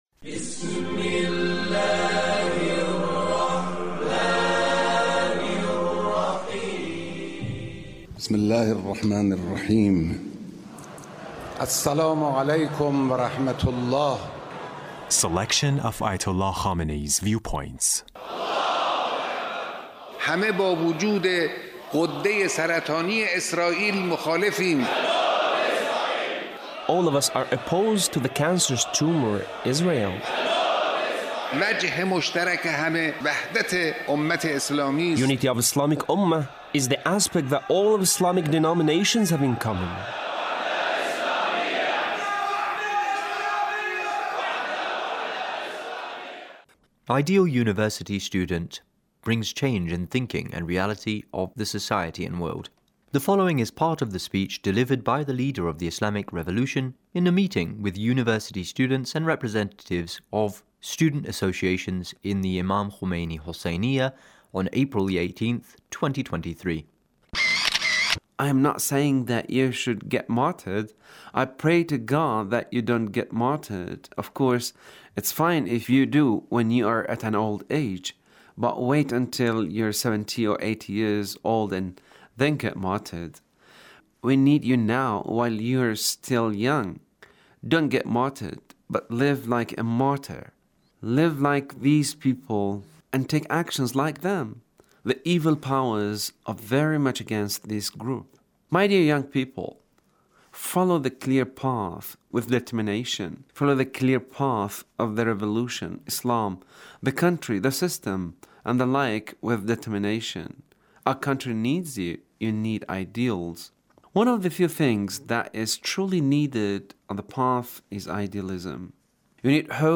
Leader's Speech (1717)
Leader's Speech with University Student